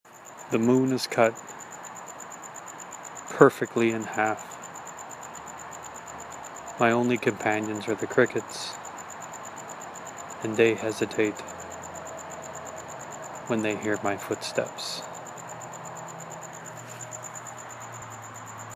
moon-and-crickets1.m4a